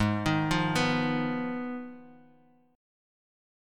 Abm6 Chord